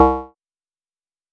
wrong.wav